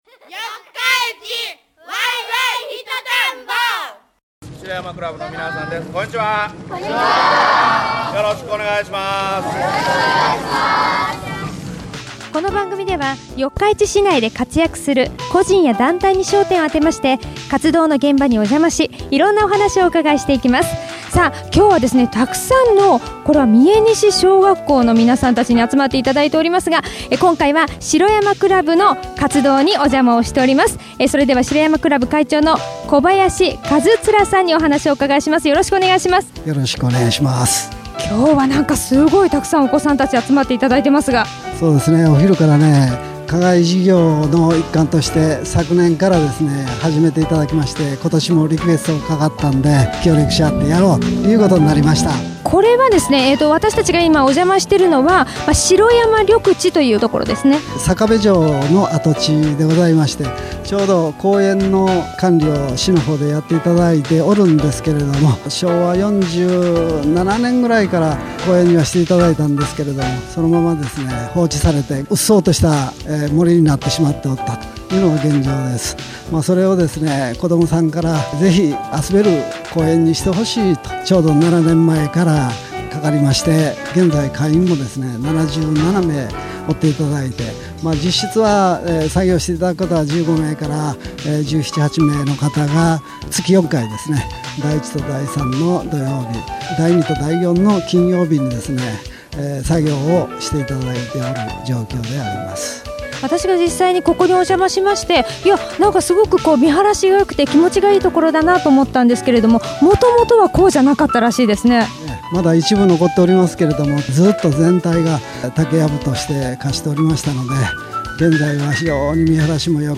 …四日市で生き生きと活動している人の活動現場の声をお届けします。